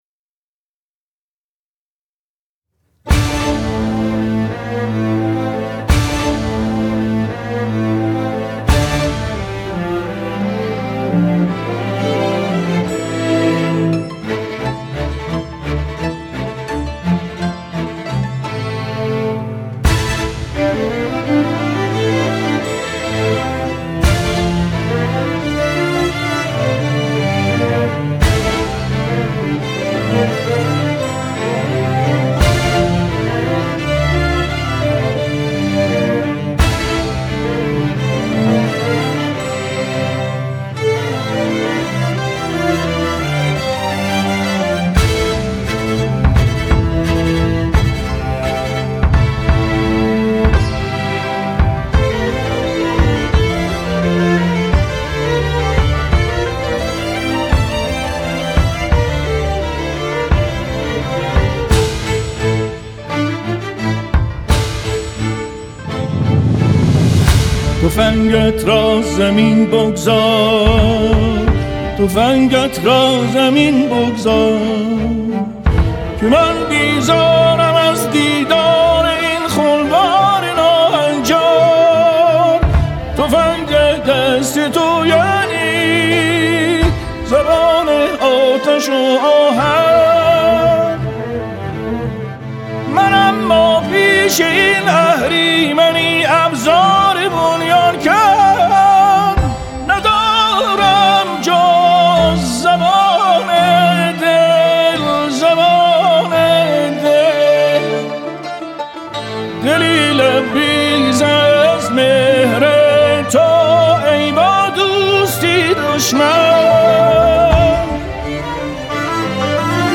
اثر جدید و طوفانی
در دستگاه شور